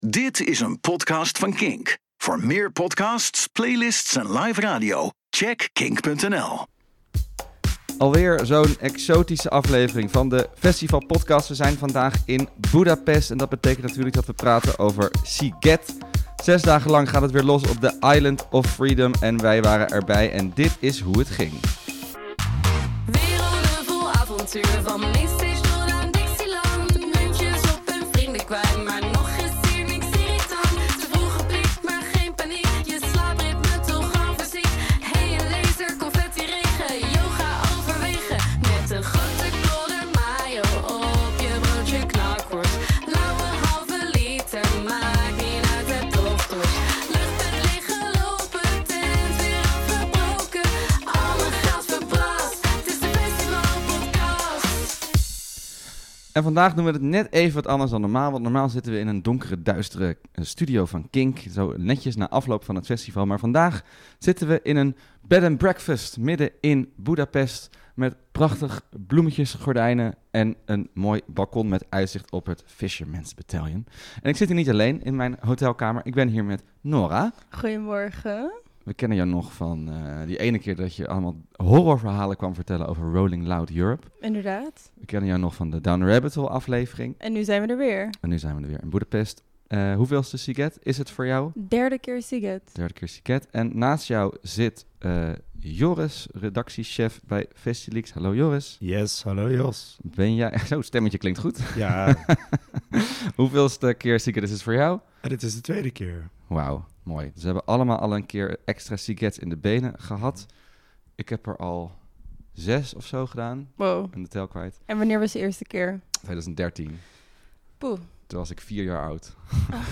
Hallo vanaf Boedapest, weer een grenzeloze aflevering van de Festivalpodcast.